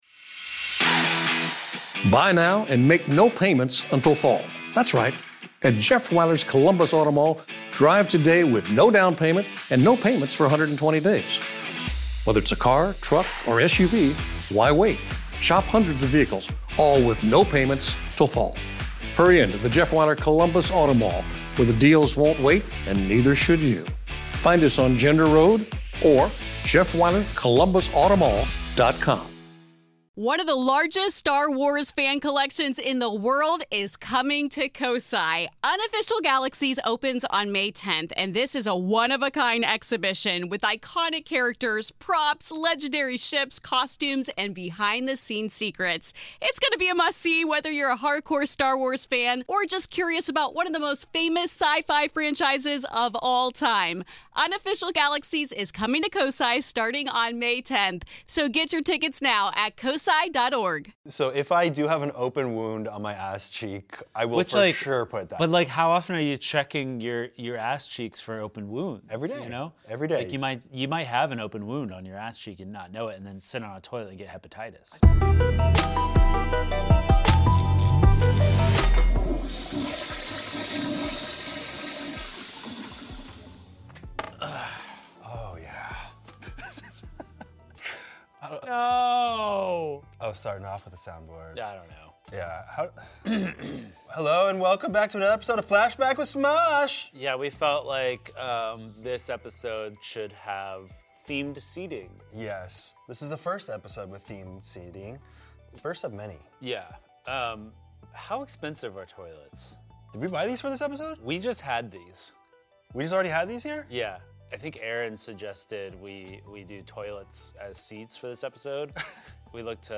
Ian and Anthony sit on toilets and talk about the making of Stuck In A Toilet.